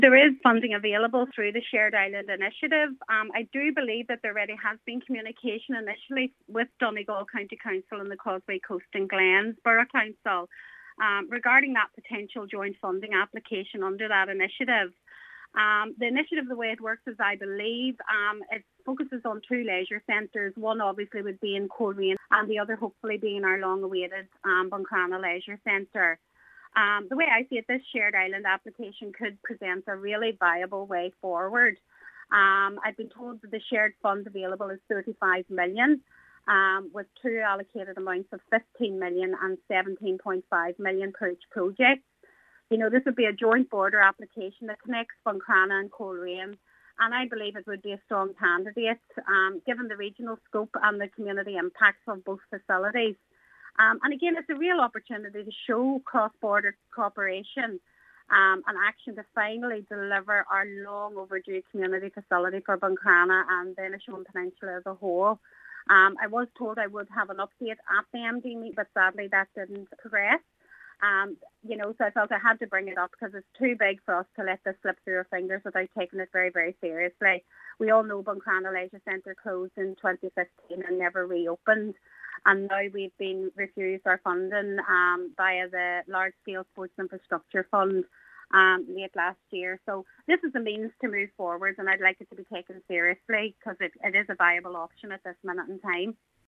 She believes it would strengthen the case for Buncrana Leisure Centre: